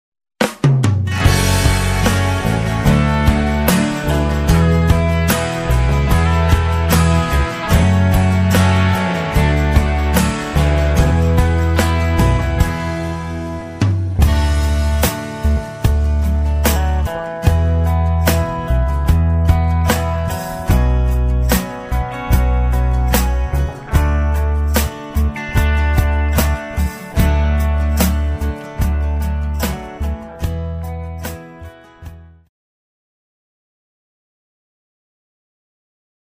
Karaoke Soundtrack
Backing Track without Vocals for your optimal performance.